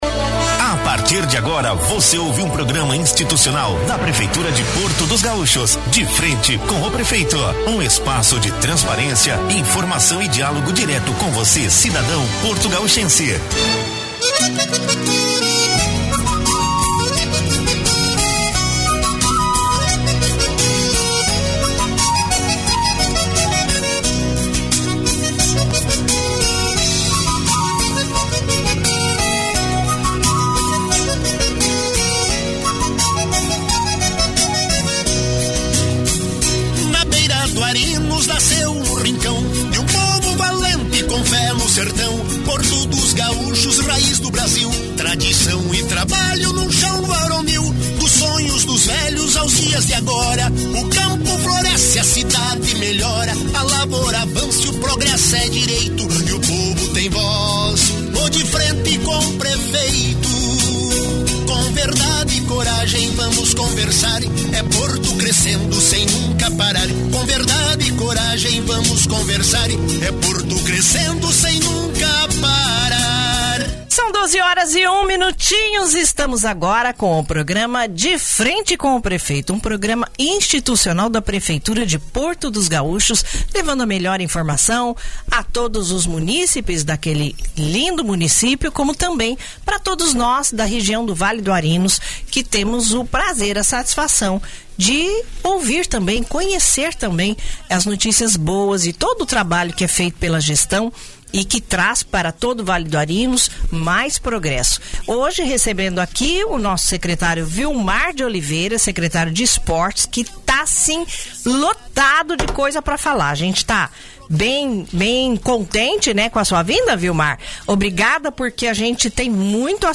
Durante o programa institucional “De Frente com o Prefeito”, exibido no dia 27 de março, o secretário Vilmar de Oliveira apresentou um panorama das ações que estão transformando o esporte em um dos pilares do desenvolvimento local.